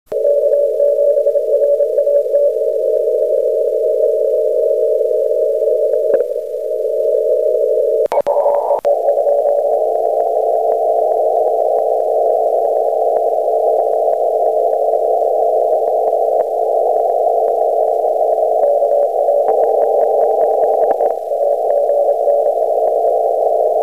コンテスト中に録音したオーディオファイルを少し整理してみました。
またリグのLine-out端子にはサイドトーンが出てこないようで、小生が送信してる部分は無音状態になってしまってます。